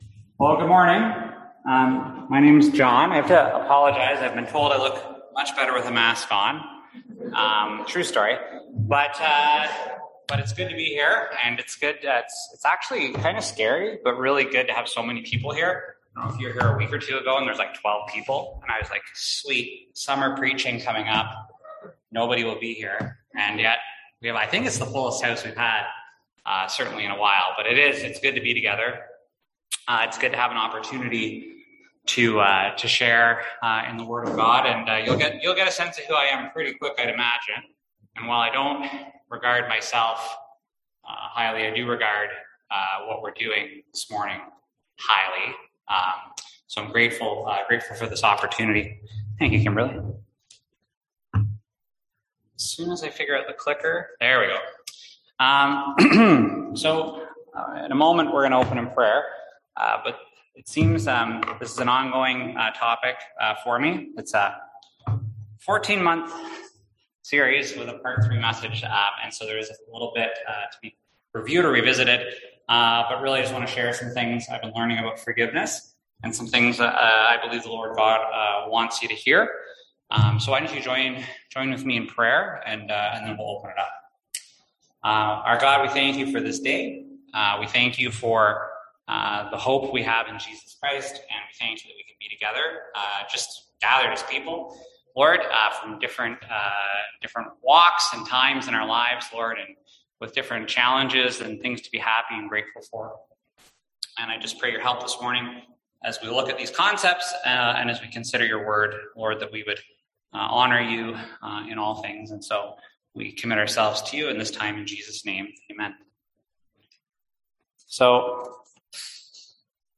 Service Type: Sunday AM Topics: Forgiveness , Reconciliation